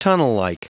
Prononciation du mot tunnellike en anglais (fichier audio)
Prononciation du mot : tunnellike